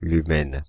Ääntäminen
France (Île-de-France): IPA: /ly.mɛn/